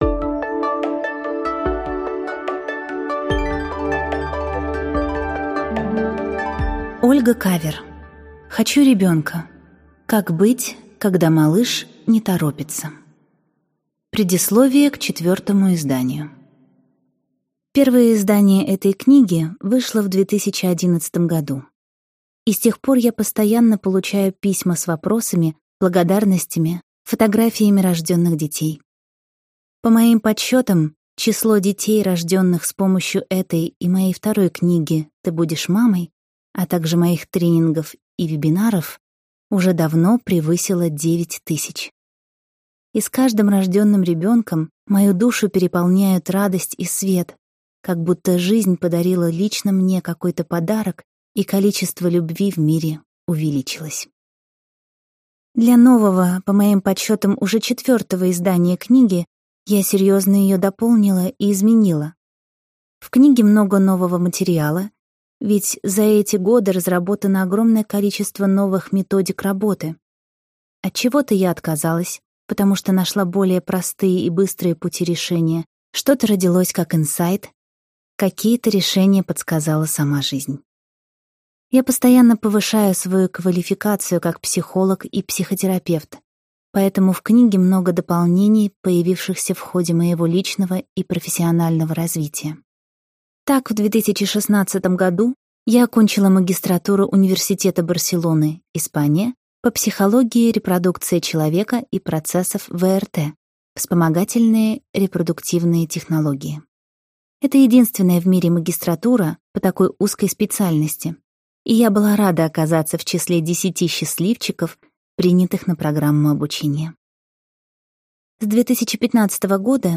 Аудиокнига Хочу ребенка: как быть, когда малыш не торопится?